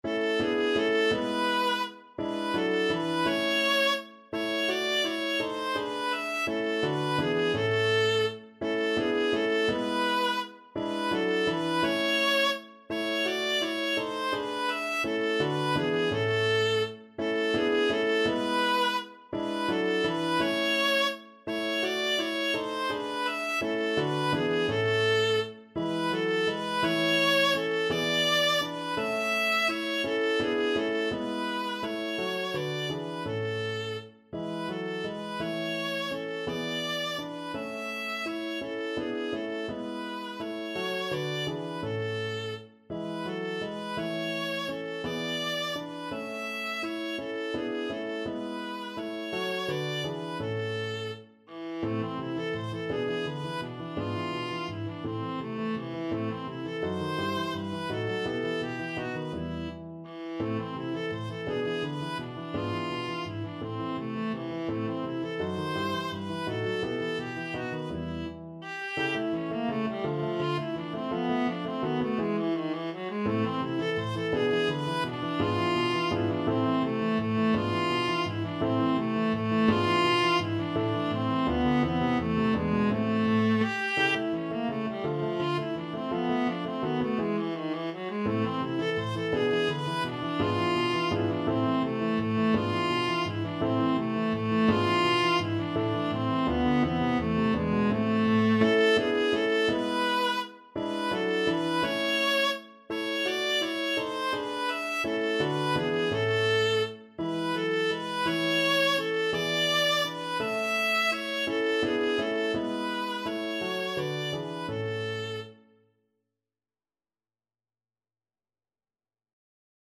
Viola version
3/8 (View more 3/8 Music)
Lustig (Happy) .=56
Classical (View more Classical Viola Music)